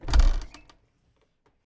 sfx_chest_open_phase_2.ogg